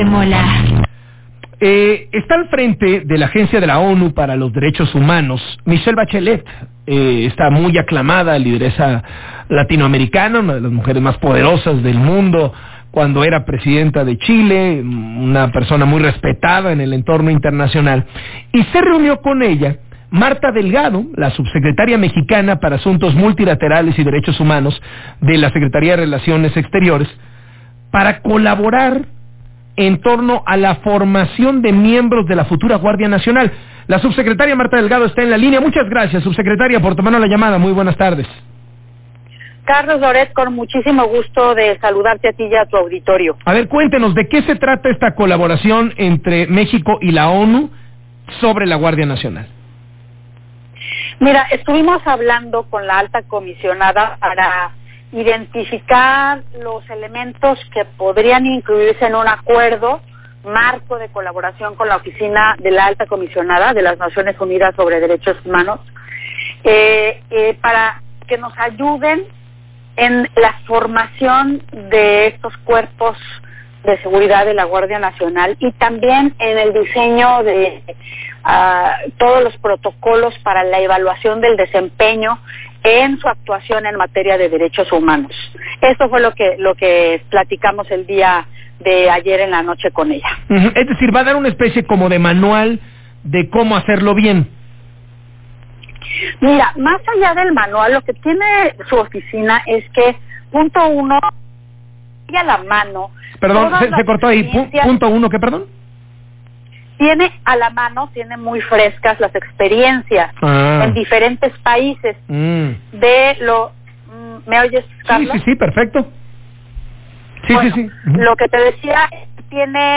[Audio] Entrevista con Carlos Loret de Mola sobre visita de Michelle Bachelet a México